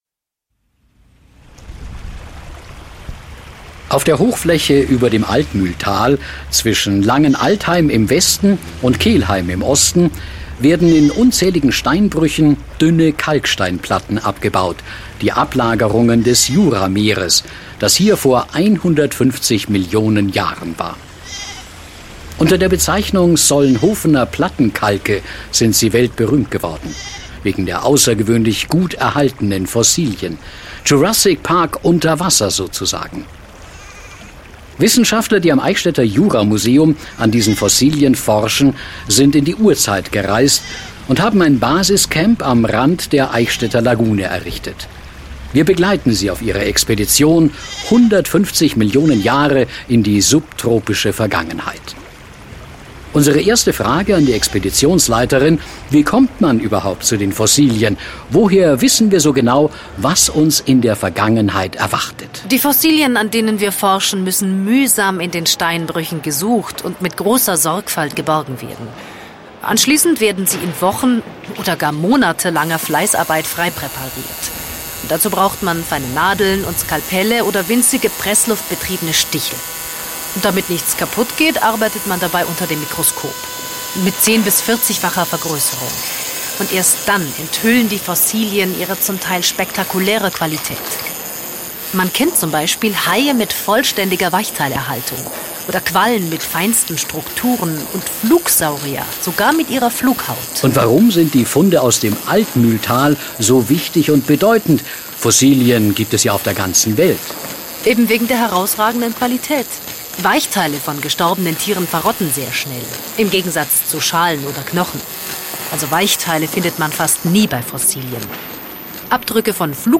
Hoerspiel